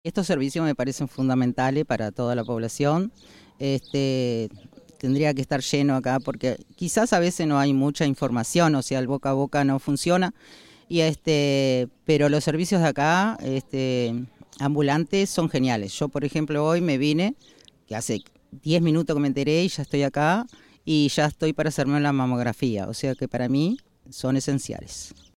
vecina de la zona que esperaba para realizarse una mamografía manifestó que "estos servicios me parecen fundamentales para toda la población